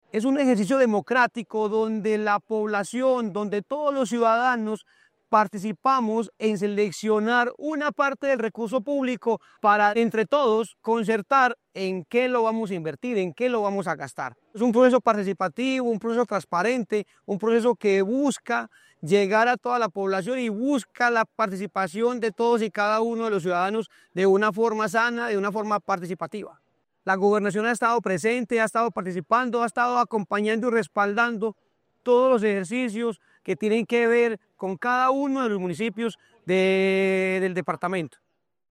El alcalde de Santuario, Miguel Antonio Bedoya Jiménez, destacó la importancia de este proceso para cada uno de los municipios y subrayó el compromiso de la Gobernación de Risaralda en su desarrollo:
MIGUEL-ANTONIO-BEDOYA-JIMENEZ-ALCALDE-DE-SANTUARIO.mp3